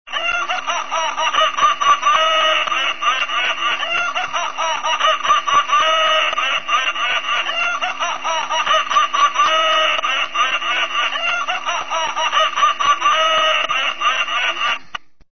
Descarga de Sonidos mp3 Gratis: reir 1.
laugh4.mp3